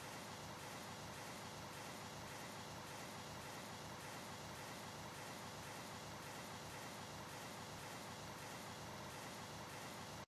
cf_quite_steady_hum.ogg